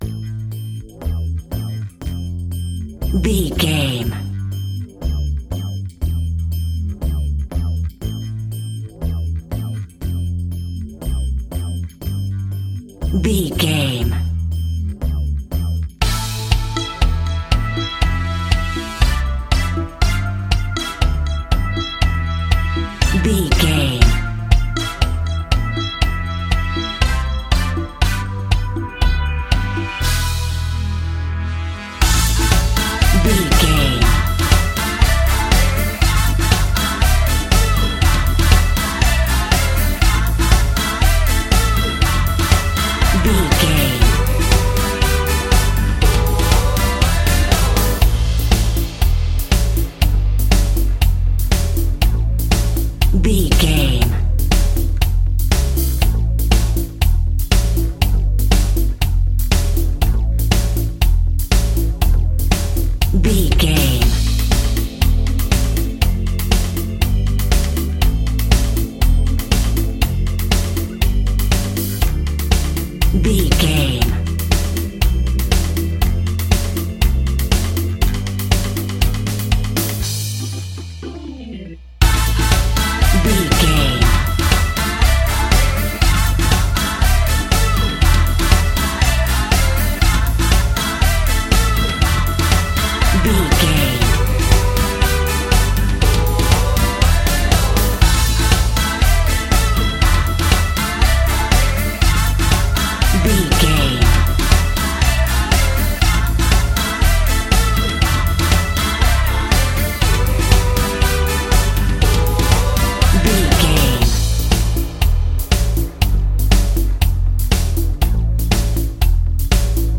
Aeolian/Minor
maracas
percussion spanish guitar